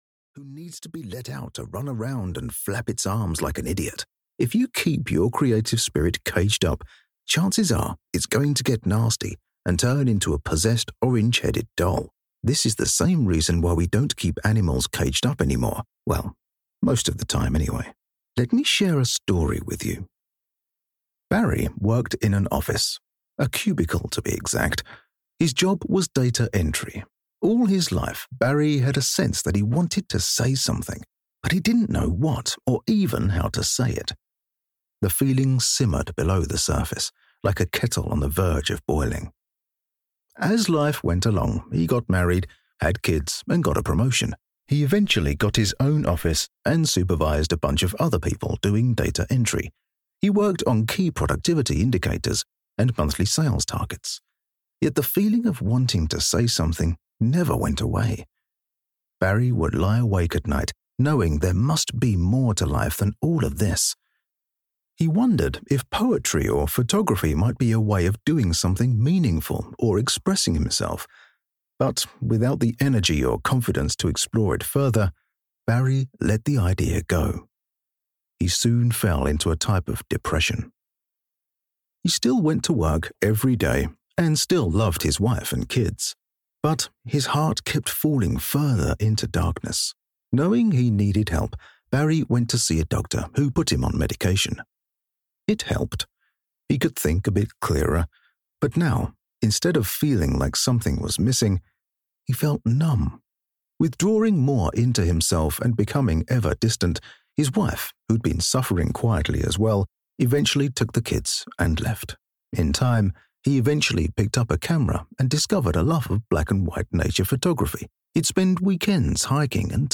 Find Your Creative Mojo: How to Overcome Fear, Procrastination and Self–Doubt to Express your True S (EN) audiokniha
Ukázka z knihy